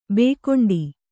♪ bēkoṇḍi